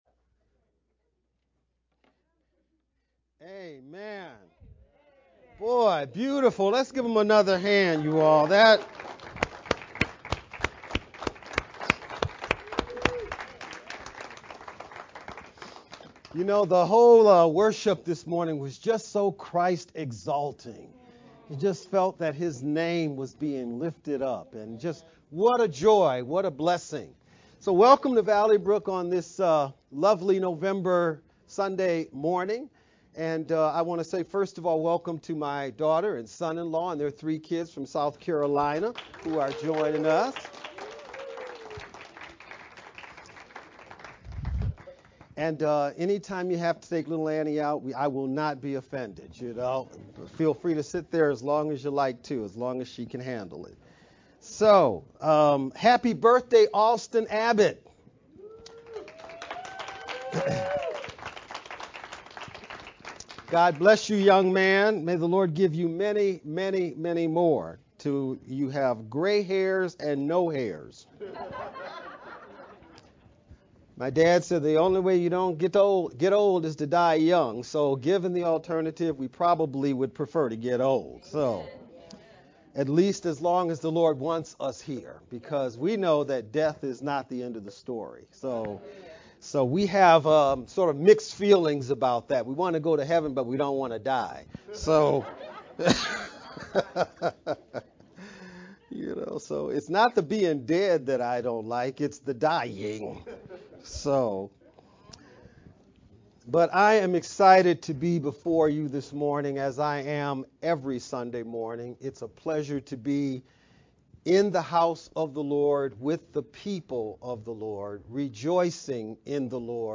Nov-20th-VBCC-Sermon-edited-sermon-only-CD.mp3